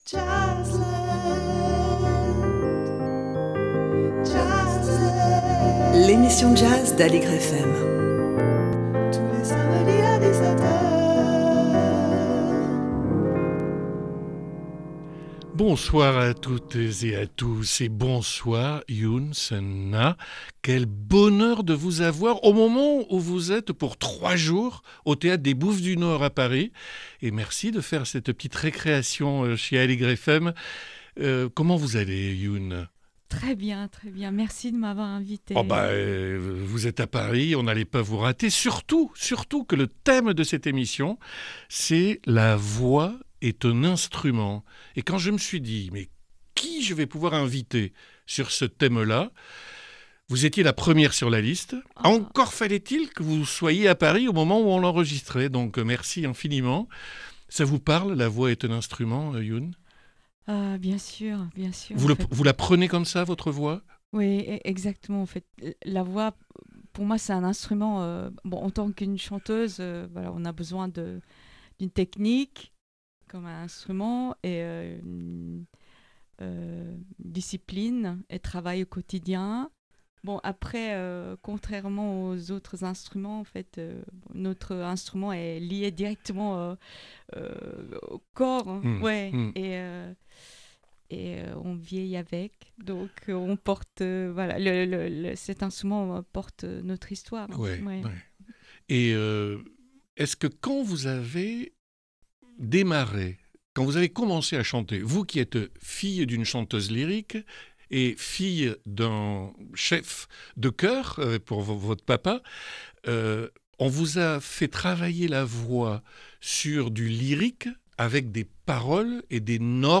La voix est un instrument: Alex Dutilh rencontre Youn Sun Nah